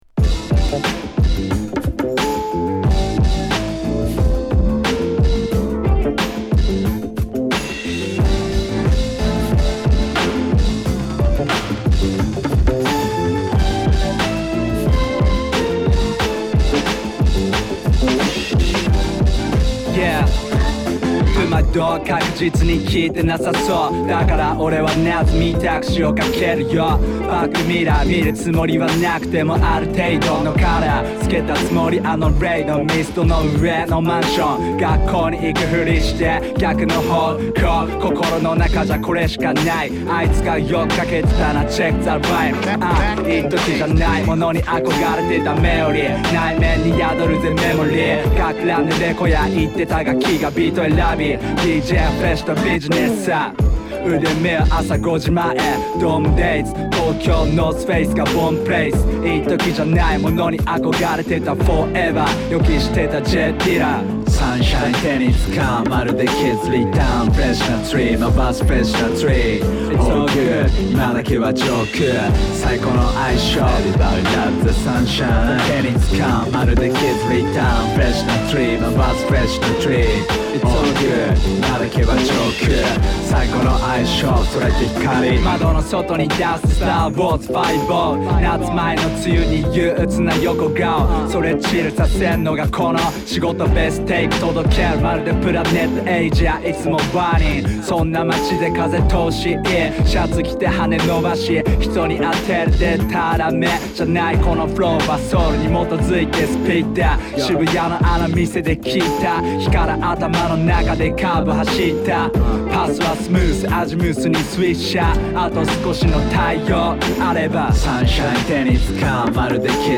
＊試聴はA→AAです。